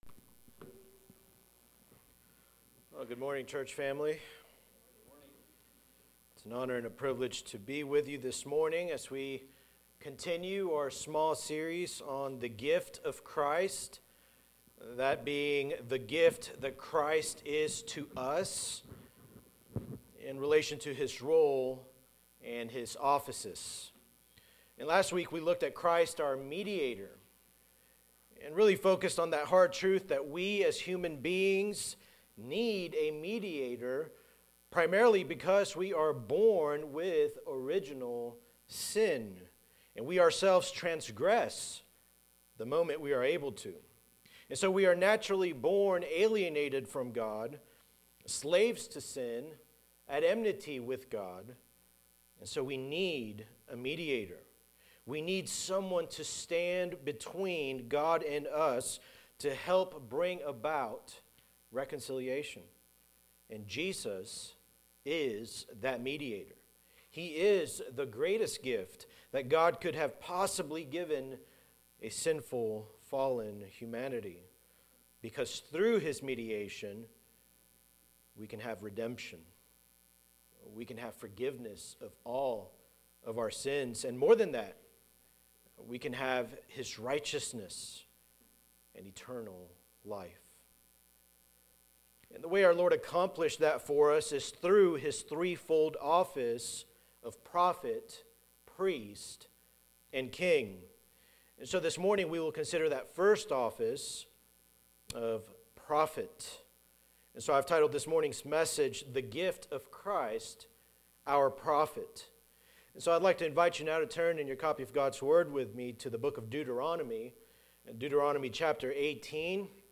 Sermons by Eatonville Baptist Church EBC